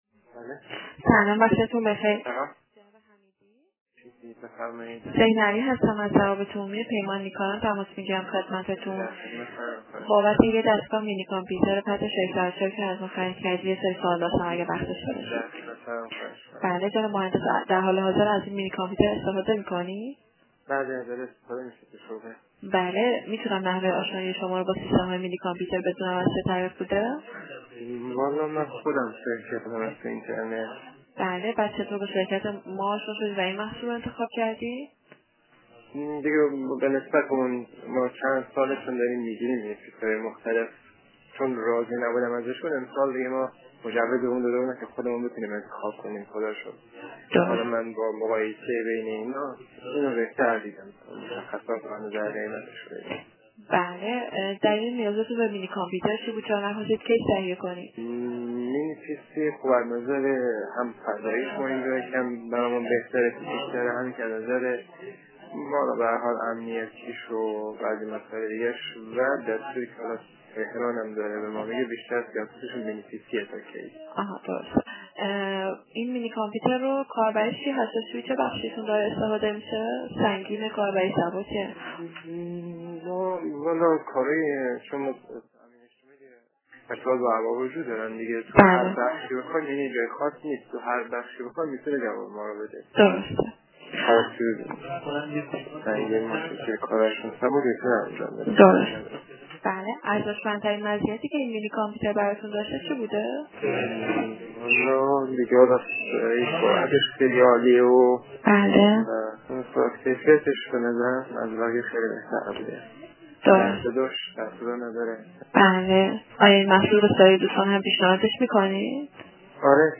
بدین منظور تعدادی مصاحبه با مشتریان عزیزمان که از مینی کامپیوتر استفاده کرده اند، گردآوری شده است.
مصاحبه مشتریان مینی کامپیوتر.mp3